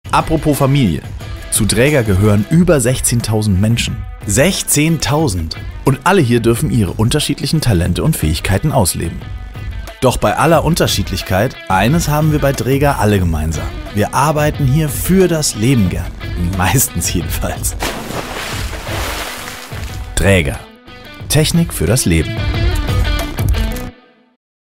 Sprechprobe: Industrie (Muttersprache):
Dräger_Imagefilm.mp3